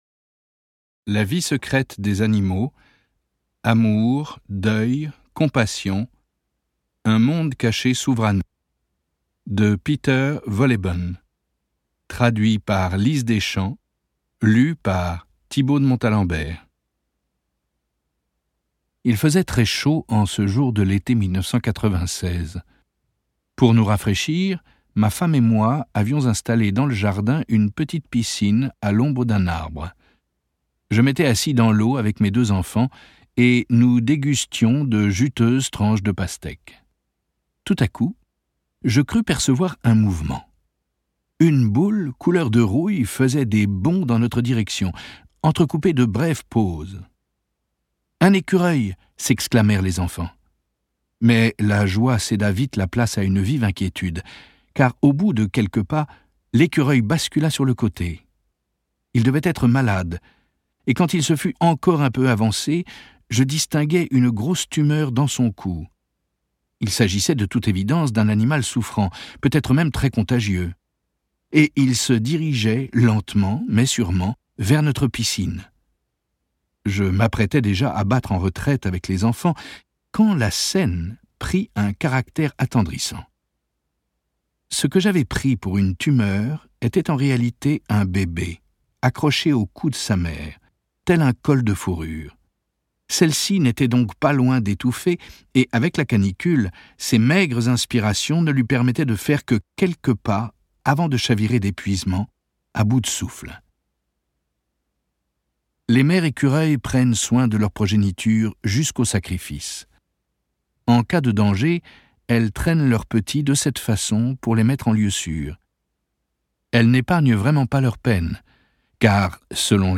Lu par Thibault de Montalembert
La Vie secrète des animaux de Peter Wohlleben (extrait, lu par Thibault de Montalembert)
Une voix de conteur idéal pour la narration de cette ouvrage, qui nous emmène dans une sorte de ballade naturaliste.